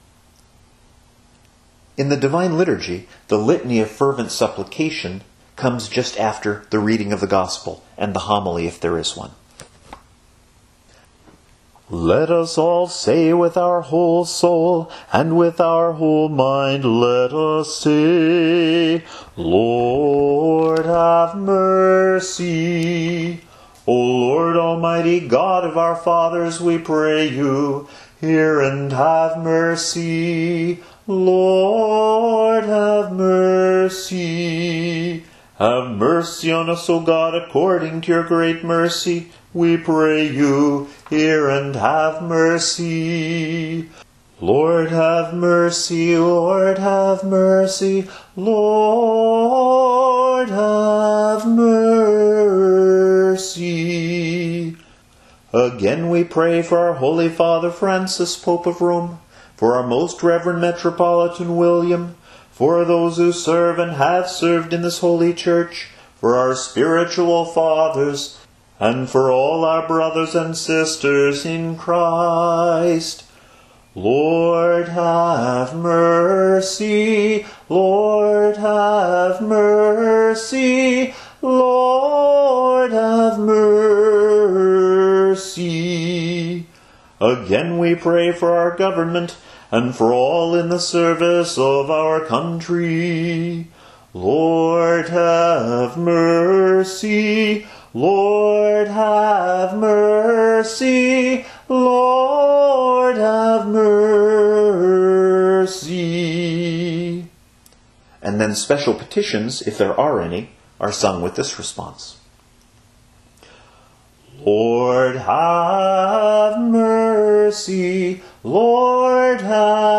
If there are special petitions, the deacon intones them now, and the response has the widest range of all, and including several leaps before returning to do.